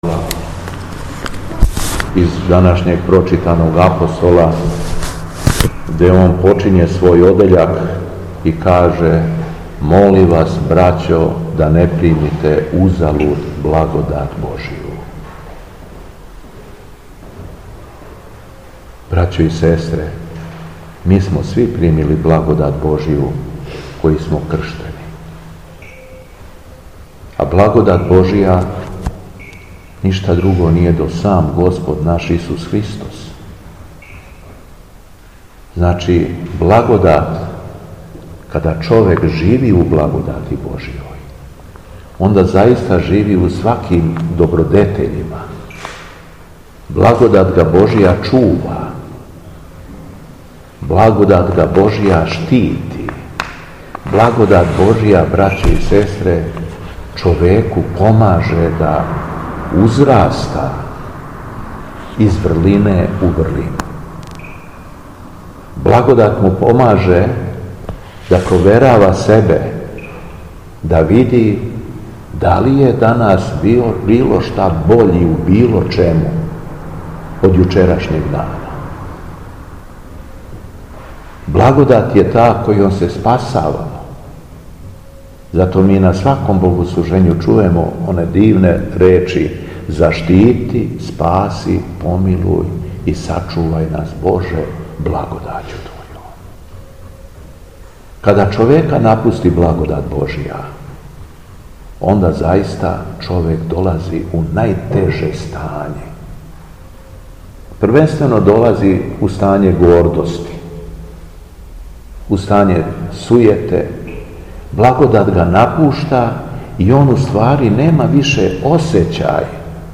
Беседа Његовог Високопреосвештенства Митрополита шумадијског г. Јована
Након прочитаног јеванђеља Митрополит се обратио сакупљеном народу: